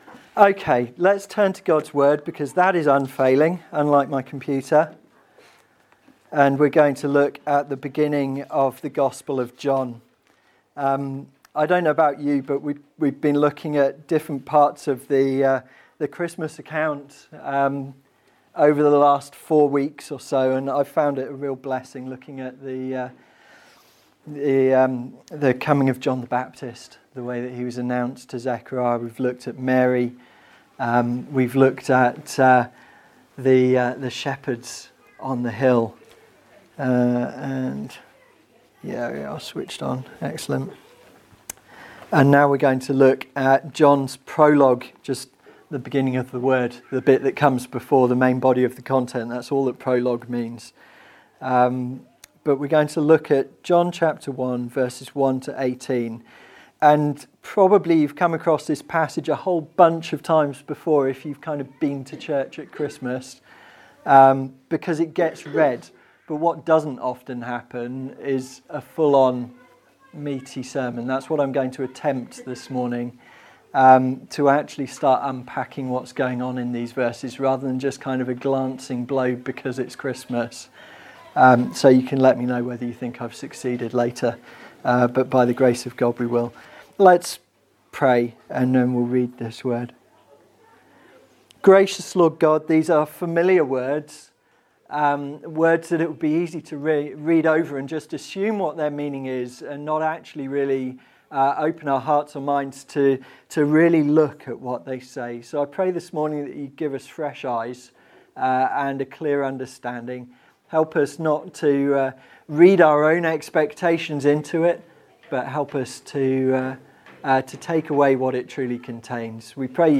In this sermon we attempt to look at it in more detail to see what it has to tell us about the God who chose to reveal himself through Christ Jesus.